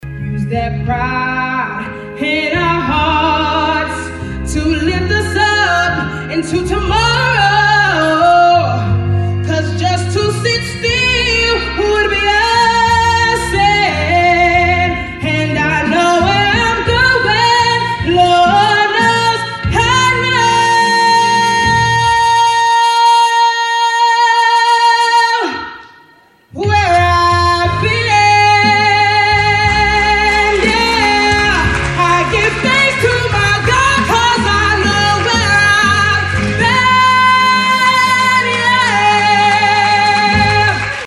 belted “A Change is Going to Come” and “I Know Where I’ve Been.”